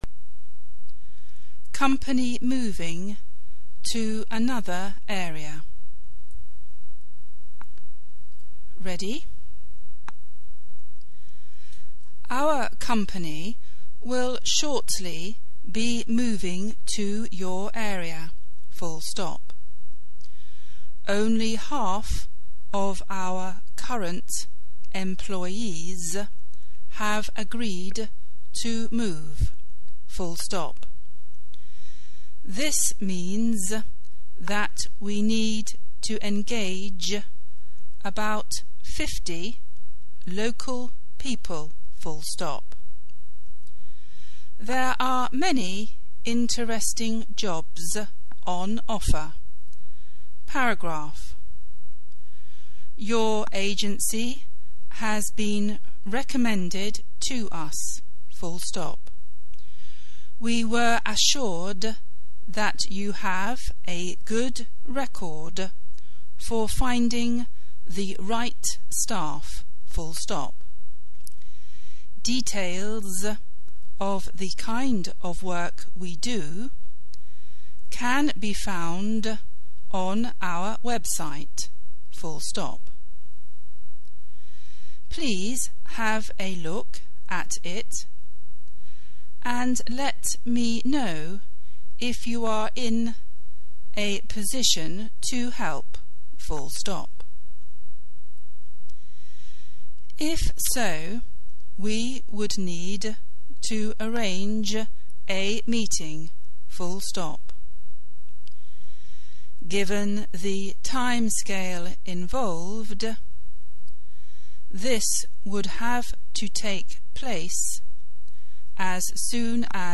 Exam Dictation
Below you will find the dictation for your Shorthand Speed Exam at 70 wpm.
Exam-Speed-Dictation-70-wpm.mp3